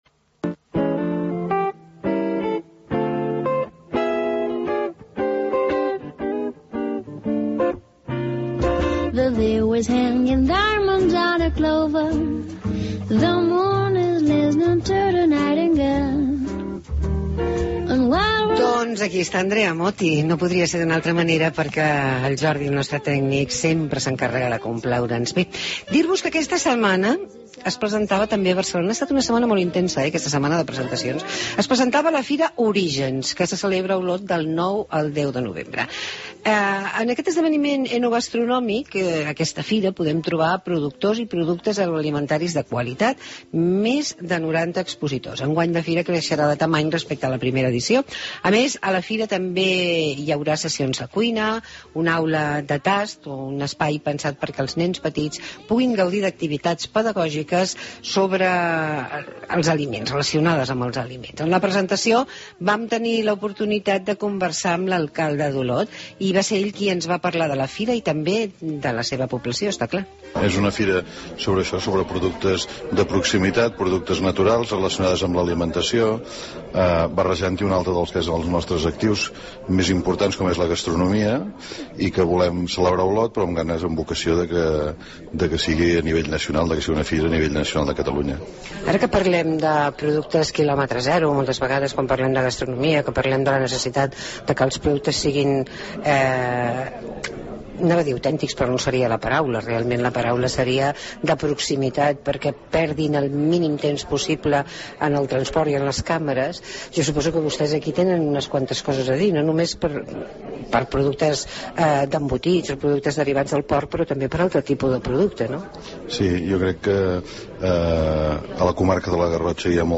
Presentació de la Fira "Origen" . Entrevista amb l'alcalde d'Olot, Josep Maria Coromines